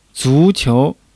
zu2-qiu2.mp3